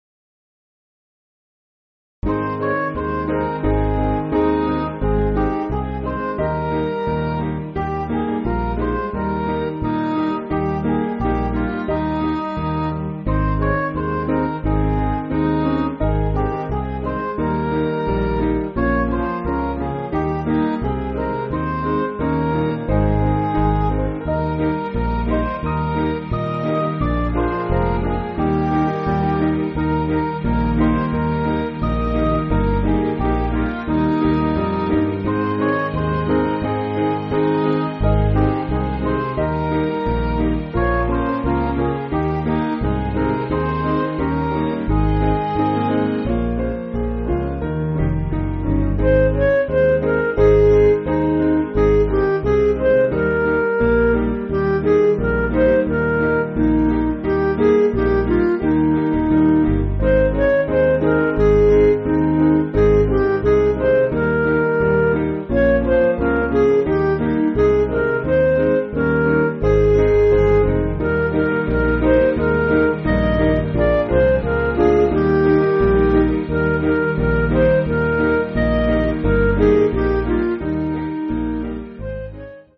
Piano & Instrumental
(CM)   3/Ab